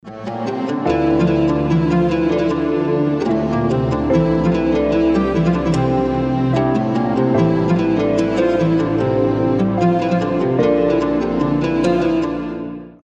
инструментальные , без слов , красивая мелодия